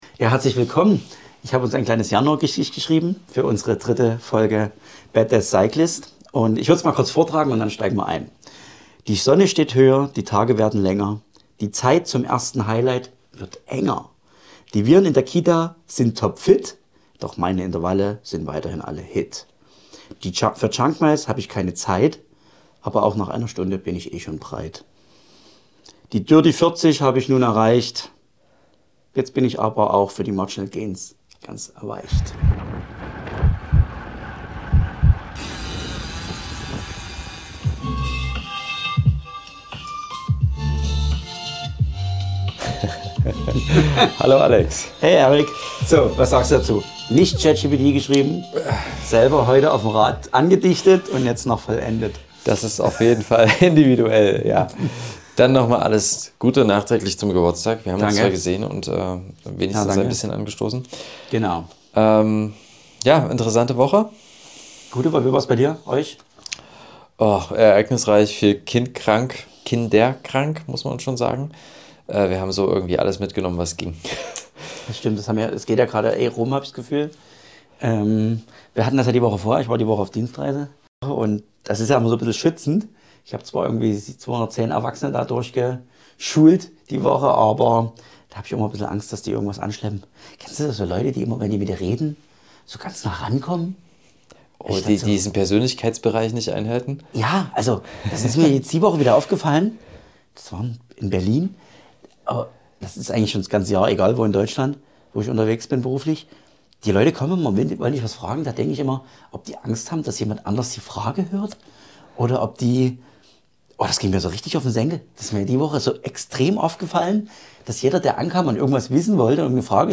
Zwei halbwissende Väter im Austausch über Alltag, Familie und Sport.
In dieser Folge sprechen zwei Väter offen und humorvoll über die finanzielle Seite des Familienlebens. Sie tauschen sich darüber aus, wie viel sie in ihre eigenen Hobbys investieren und vergleichen das mit dem Taschengeld ihrer Kinder.